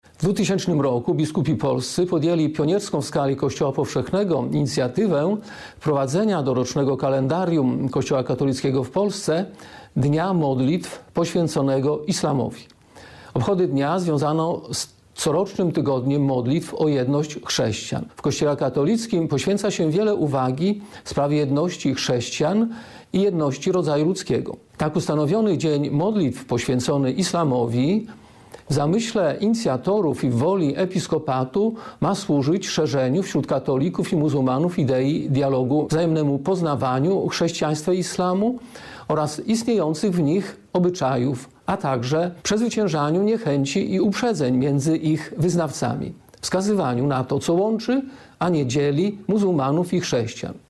Mimo kluczowych różnic między katolikami a muzułmanami, w Polsce prowadzone są działania mające na celu dialog międzyreligijny. Prowadzi je Rada Wspólna Katolików i Muzułmanów, a także polski Episkopat, zaznacza bp Henryk Ciereszko, delegat KEP ds. Dialogu Katolików i Muzułmanów.